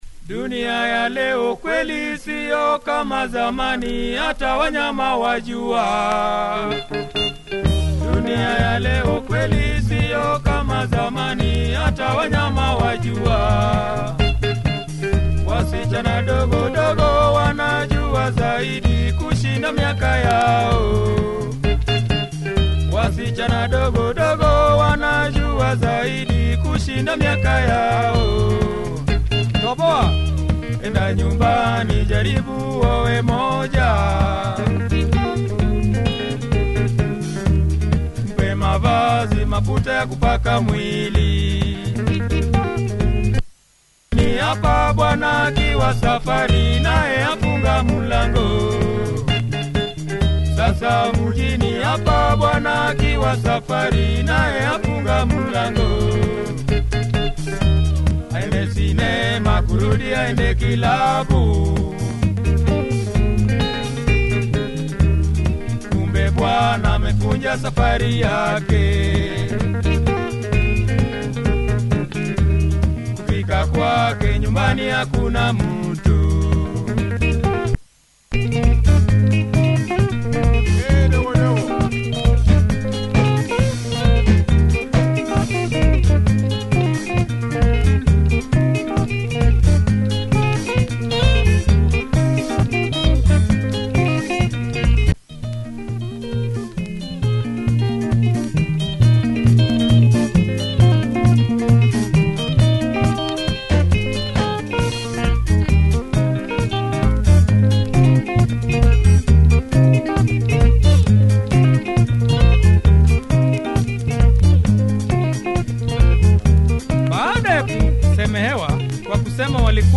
Nice drive in this track, on this cool label!